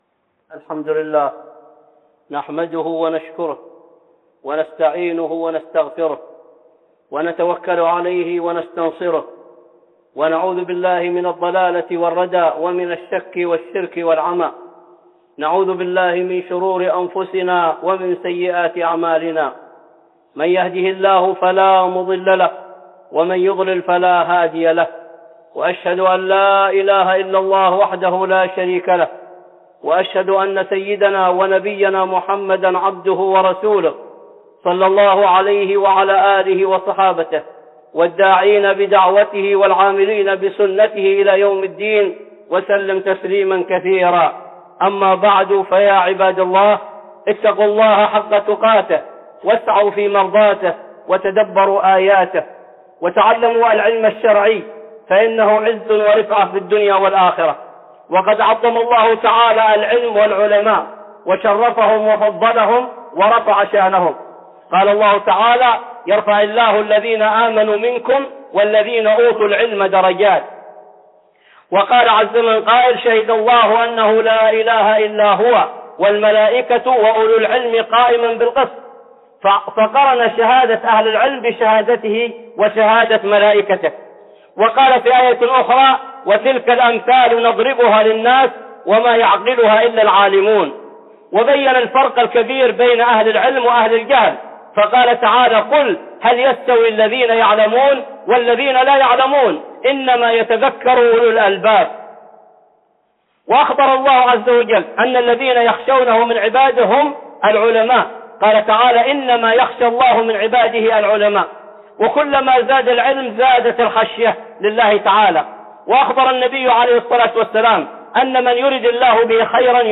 (خطبة جمعة) العلم الذي لا ينفع